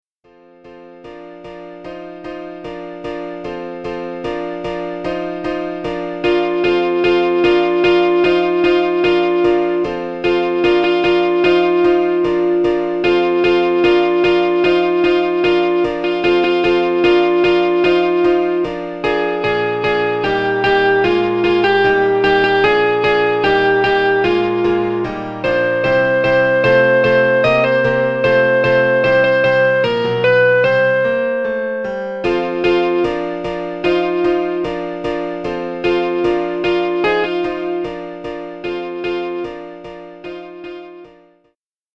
Sample from the Rehearsal CD
An educational, historical, rhyming comedy musical.